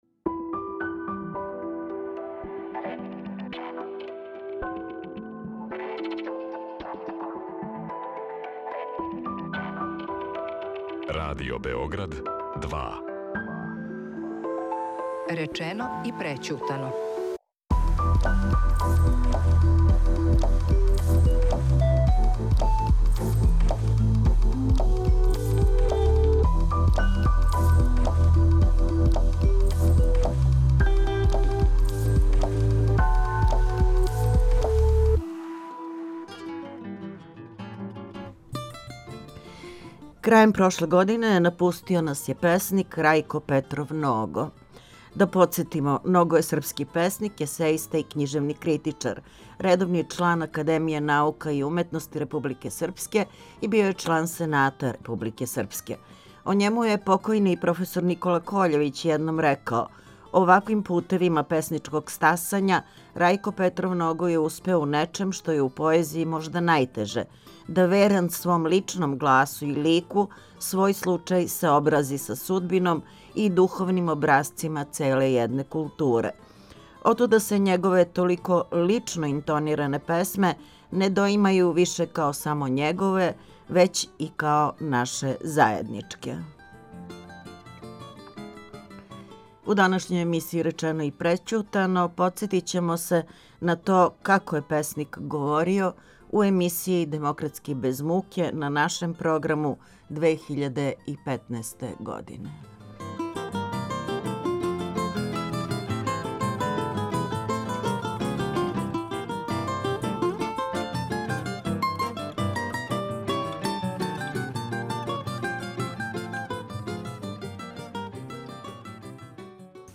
Разговор вођен у емисији Демократски без муке, 2015. године.